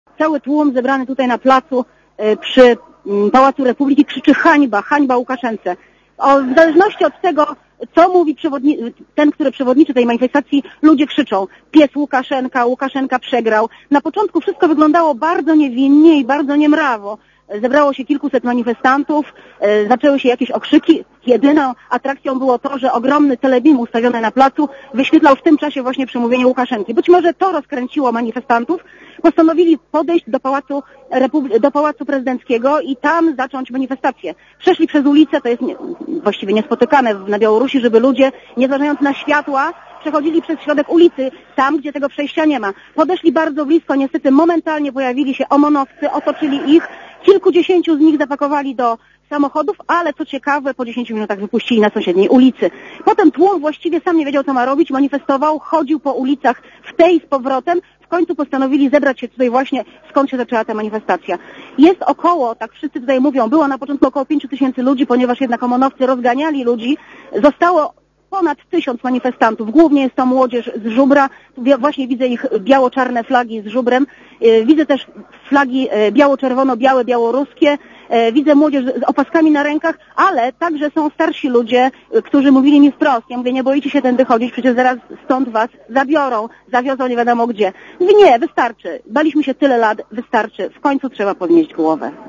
Gorąco w stolicy Białorusi. W Mińsku odbywa się manifestacja młodzieży przeciw prezydentowi Aleksandrowi Łukaszence.
bialorus-minsk-manifestacja.mp3